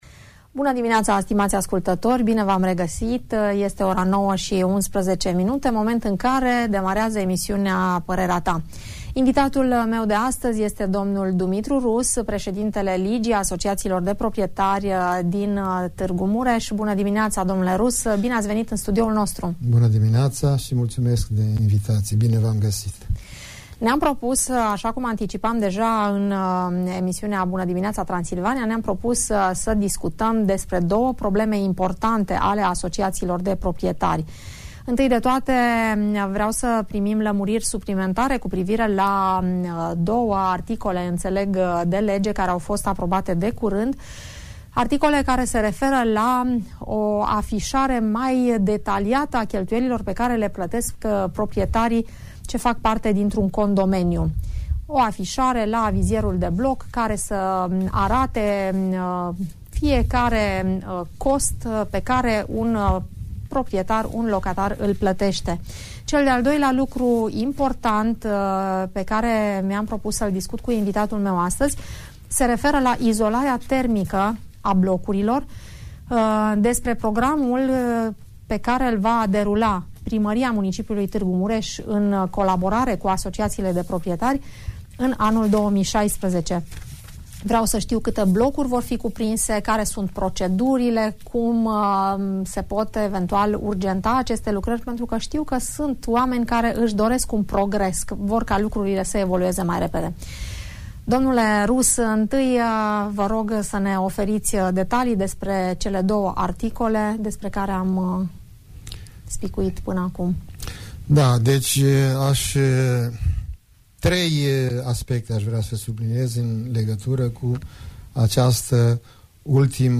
Invitat la „Parerea ta”